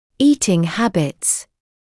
[‘iːtɪŋ ‘hæbɪts][‘иːтин ‘хэбитс]пищевые привычки; режим питания